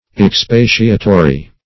Expatiatory \Ex*pa"ti*a*to*ry\, a.
expatiatory.mp3